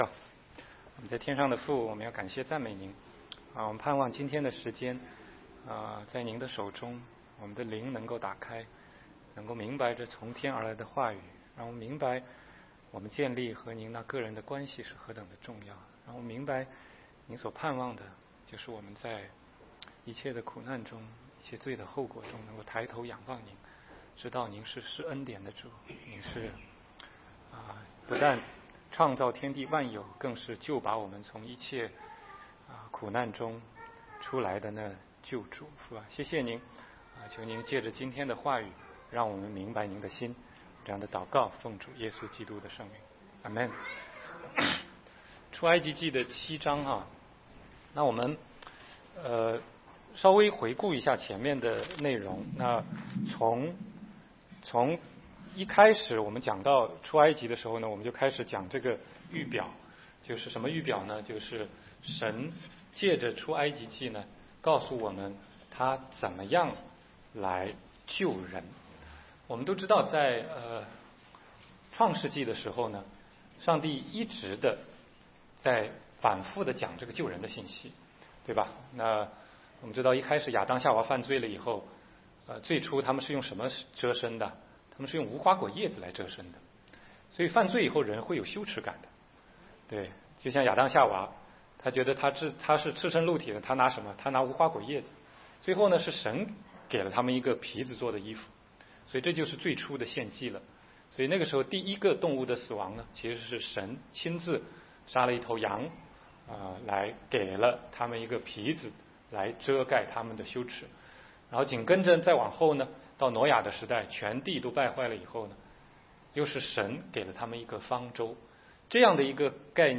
16街讲道录音 - 十灾中神的恩典